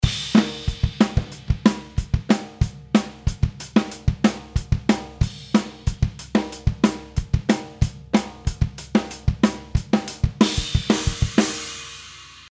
まず、キックのINマイク（バスドラムの内側で収録しているマイク）の音を作ってみましょう。
プラグインを立ち上げた時はフラットですね。